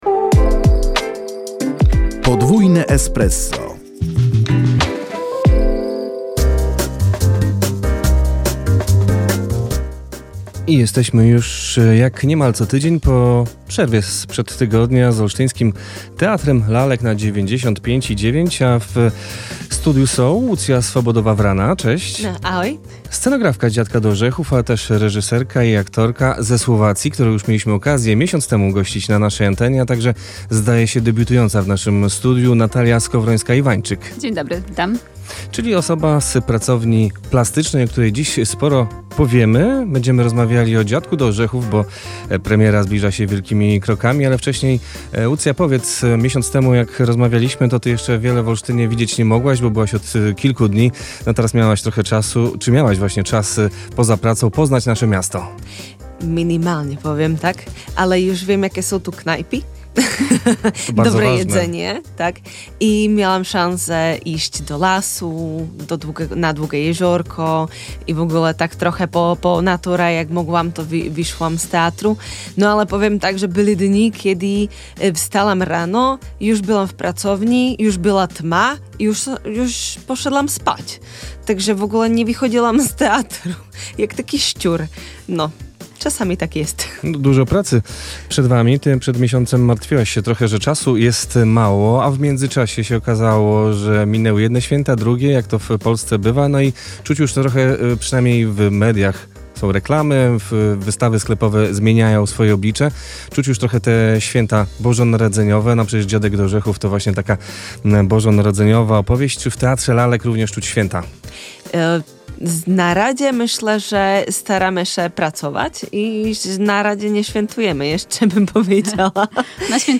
Rozmowa dotyczyła pracy nad spektaklem „Dziadek do orzechów” oraz działalności pracowni.